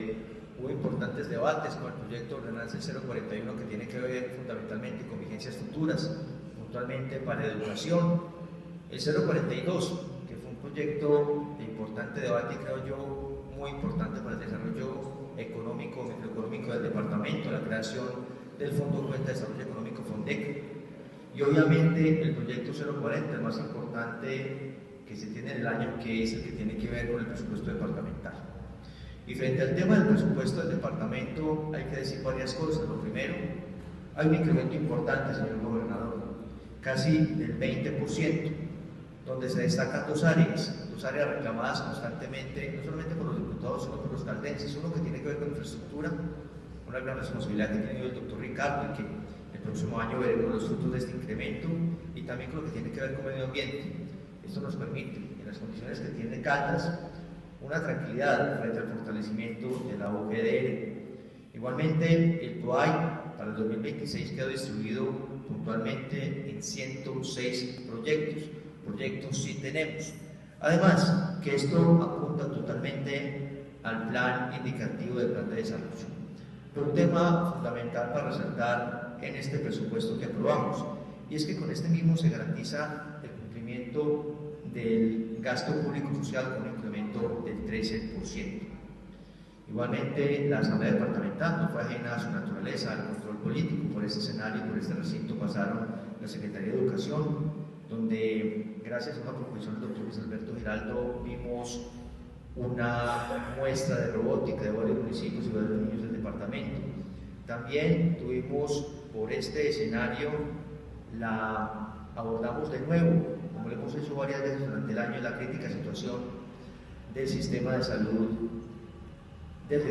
Presidente de la Asamblea de Caldas, Hernán Alberto Bedoya.